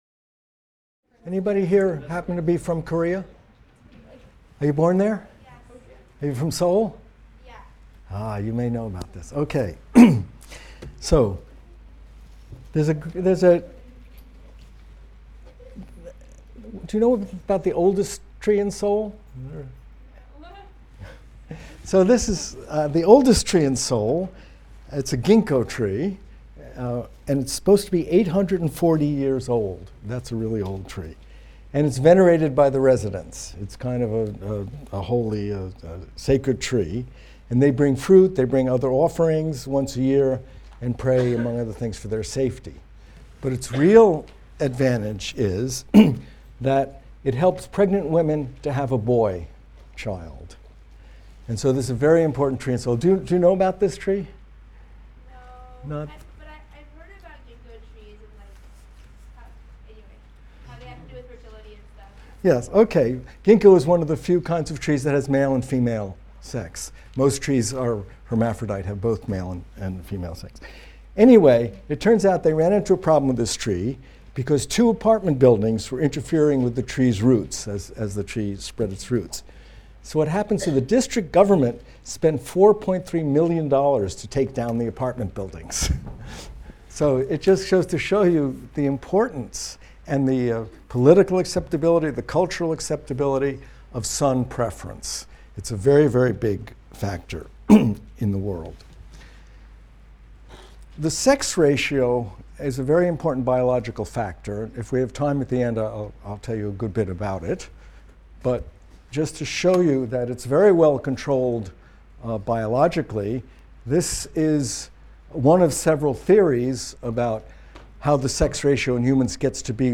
MCDB 150 - Lecture 15 - Female Disadvantage | Open Yale Courses